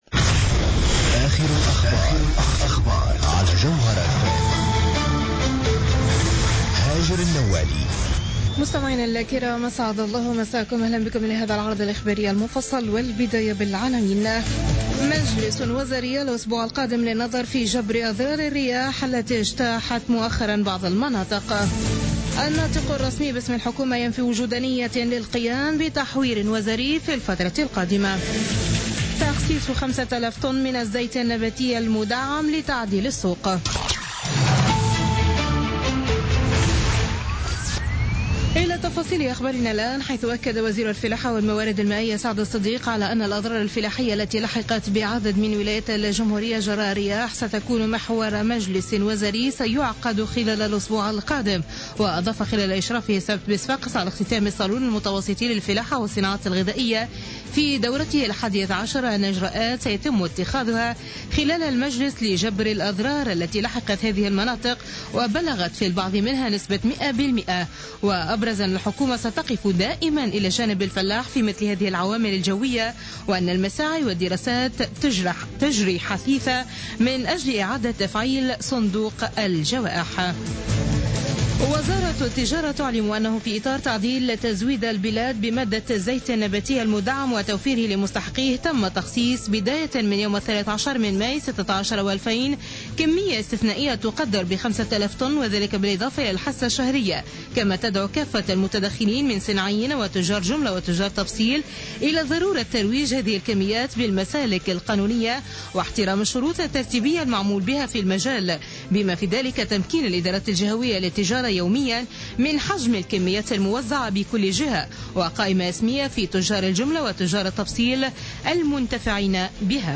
نشرة أخبار منتصف الليل ليوم الأحد 15 ماي 2016